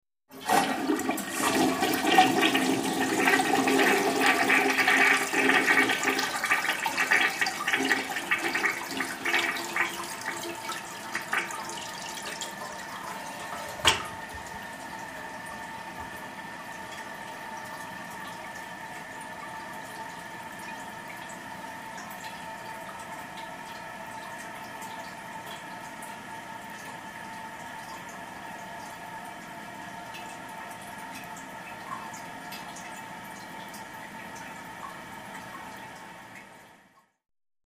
Toilet Flush 2; Typical Flush Routine; Handle Is Pressed, Water Then Flows Abruptly With Lots Of Water Gurgles Which Fades Into Typical Tank Refill Hums, Drips, And Hisses. Close Perspective.